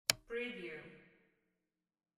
Coffee maker switch sound effect .wav #2
Description: The sound of turning a coffee maker on or off
Properties: 48.000 kHz 16-bit Stereo
A beep sound is embedded in the audio preview file but it is not present in the high resolution downloadable wav file.
Keywords: coffee, tea, hot, water, electric, express, kettle, switch, turn, turning, on, off, click, press, push, button
coffee-maker-switch-preview-2.mp3